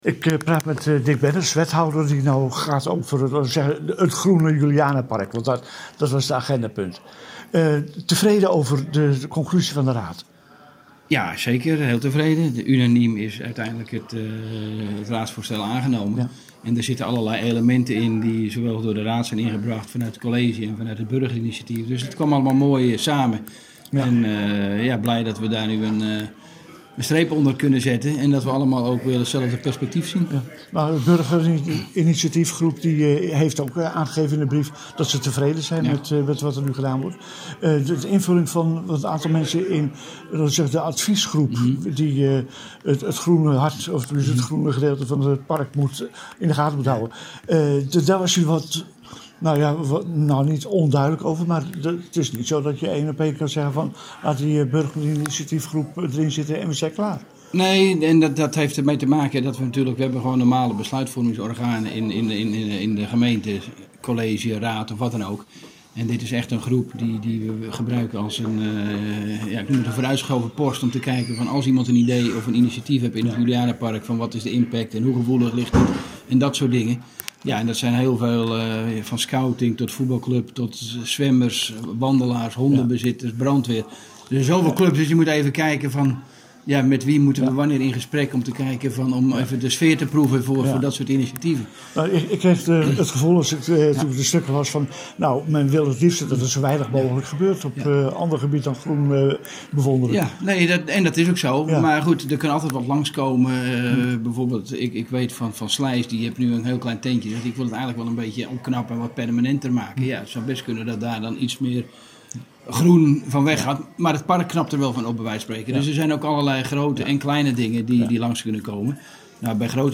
Gesprek met wethouder Dick Bennis over het Julianapark: